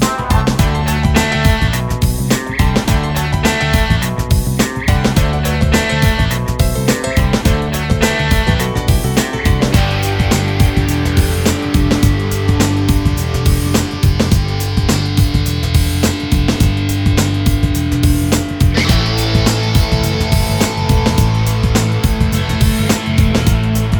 no Backing Vocals Indie / Alternative 5:00 Buy £1.50